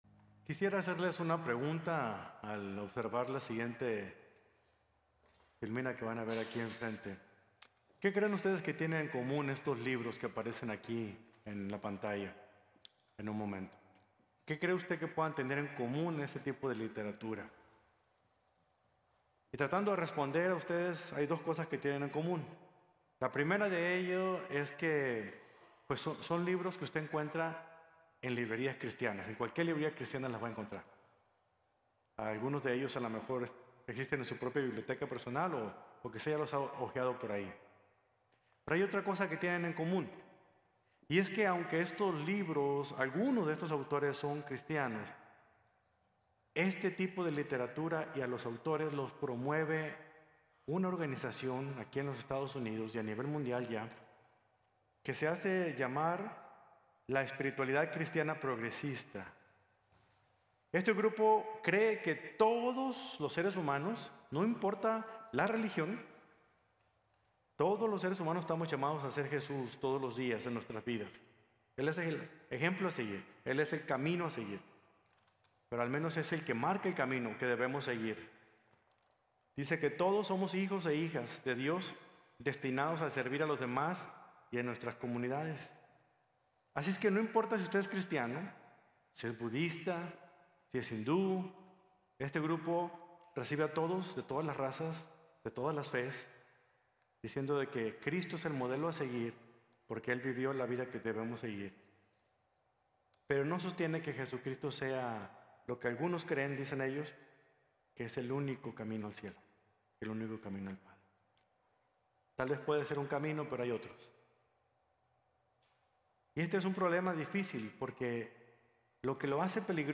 Completos en Cristo | Sermon | Grace Bible Church